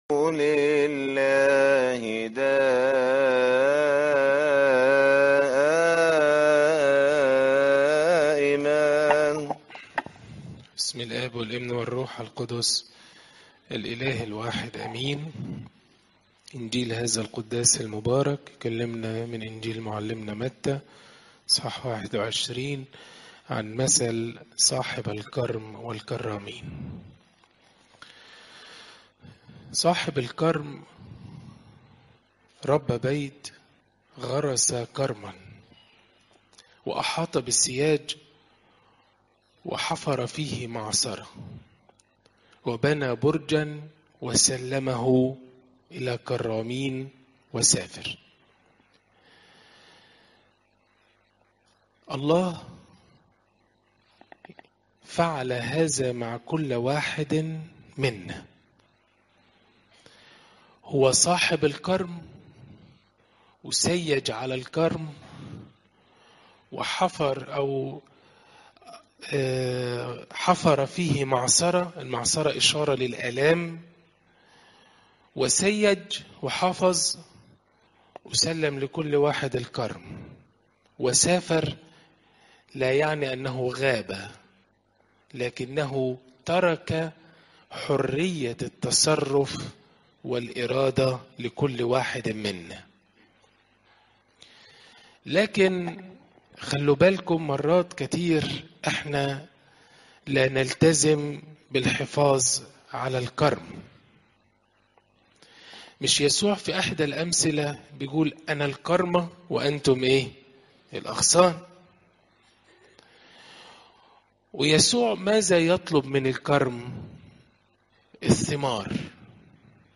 تفاصيل العظة